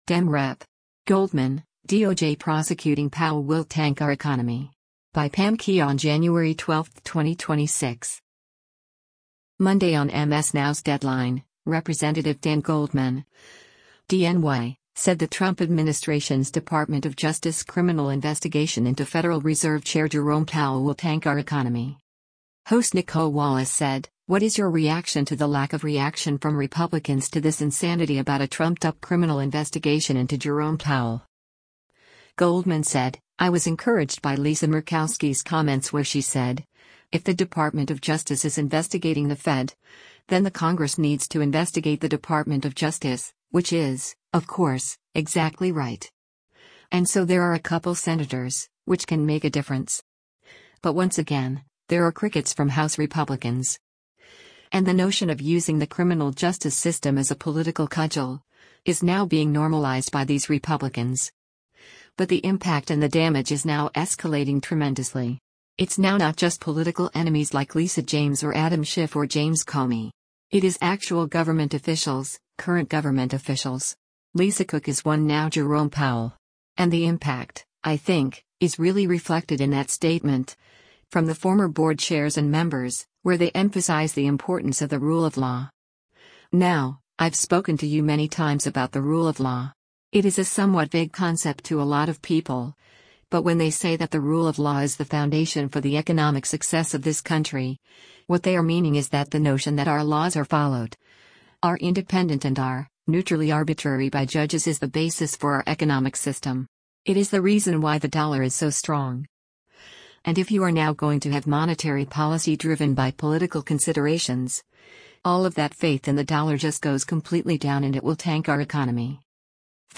Monday on MS NOW’s “Deadline,” Rep. Dan Goldman (D-NY) said the Trump administration’s Department of Justice criminal investigation into Federal Reserve Chair Jerome Powell “will tank our economy.”
Host Nicolle Wallace said, “What is your reaction to the lack of reaction from Republicans to this insanity about a trumped up criminal investigation into Jerome Powell?”